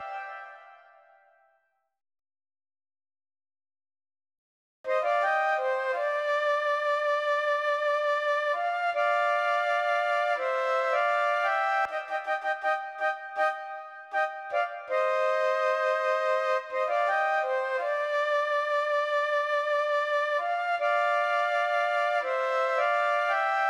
06 flute 1B.wav